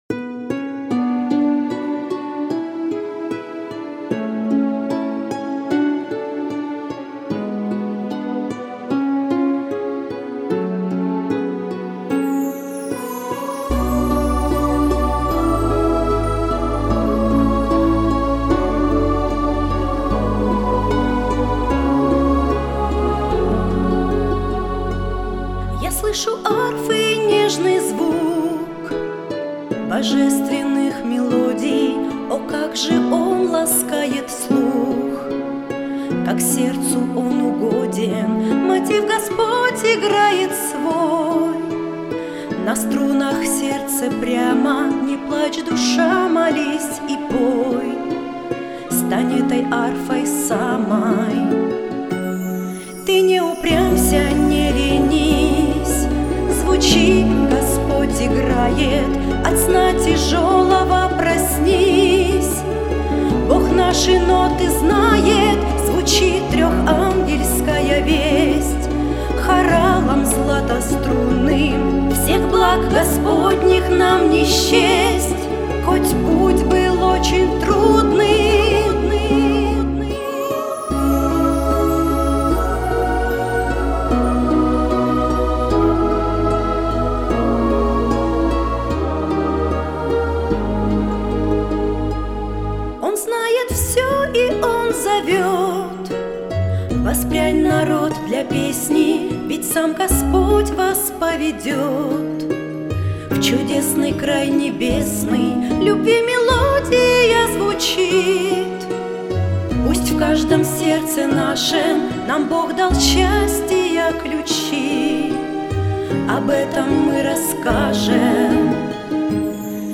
песня
151 просмотр 437 прослушиваний 26 скачиваний BPM: 81